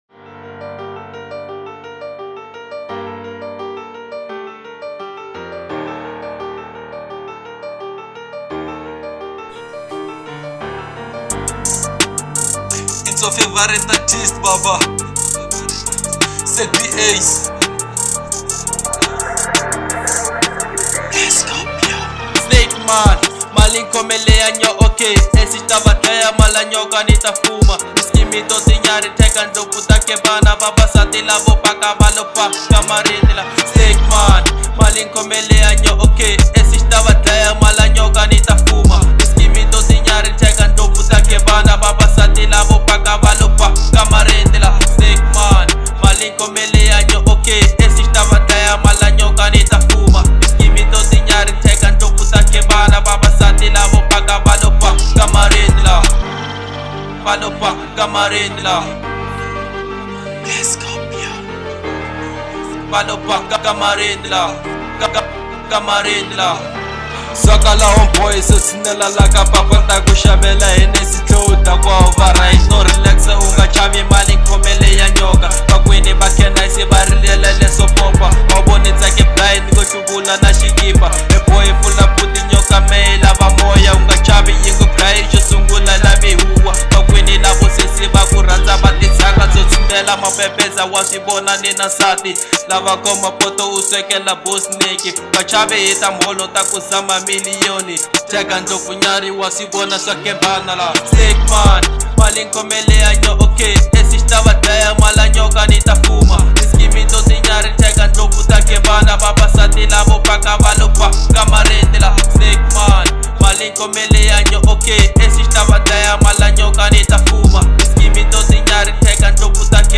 03:00 Genre : Hip Hop Size